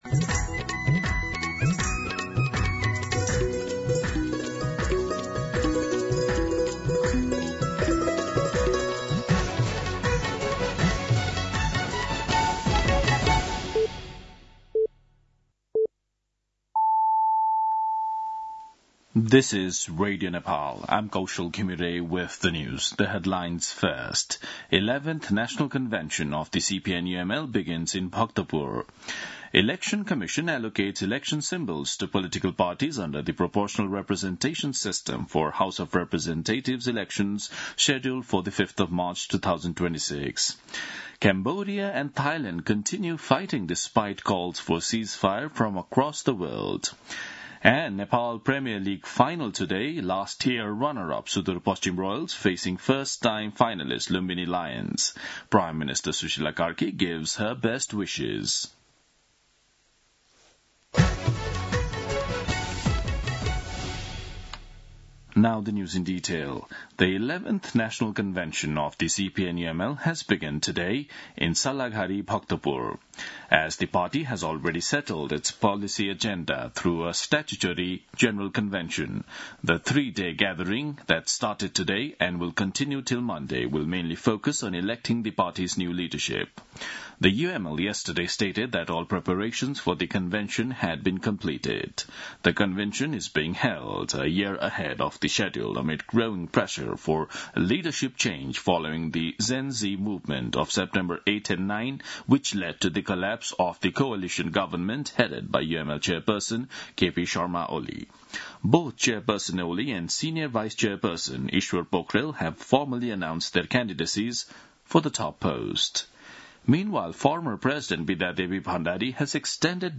दिउँसो २ बजेको अङ्ग्रेजी समाचार : १८ पुष , २०२६
2pm-English-News-27.mp3